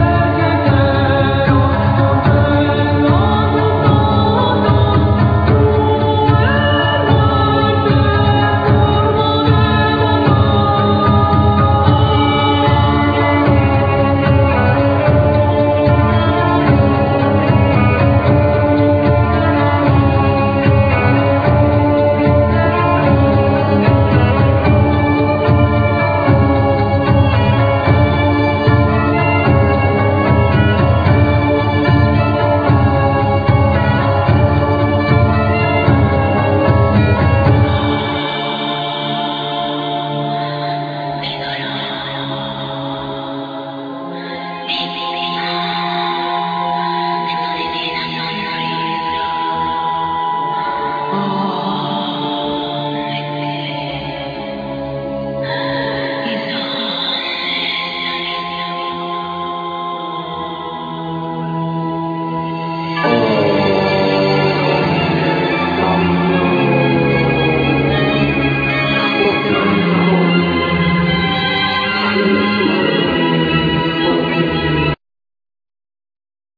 Vocal, Percussions
Keyboards, Backing vocal, Drums
Violin, Backing vocal